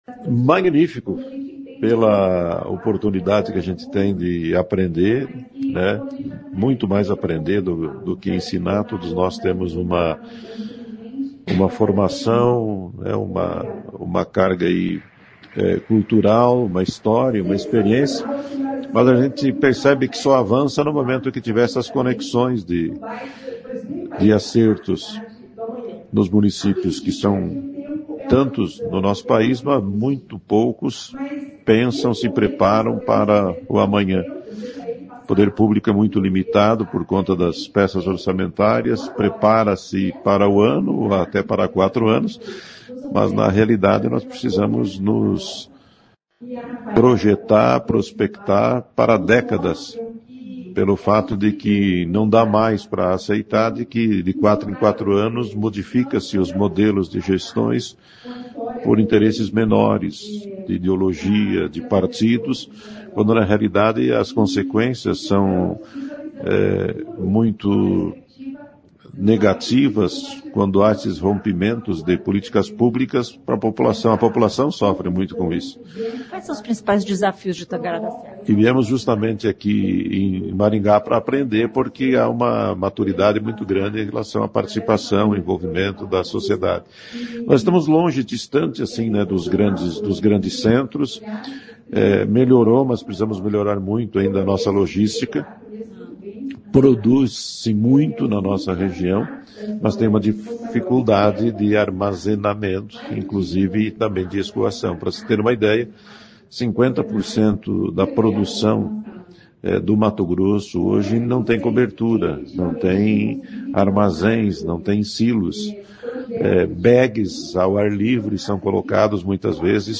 O secretário de Desenvolvimento Econômico de Tangará da Serra, em Mato Grosso,  Silvio José Somavilla, ficou animado com o que ouviu no evento.
A entrevista foi realizada no estúdio móvel CBN instalado no local do evento.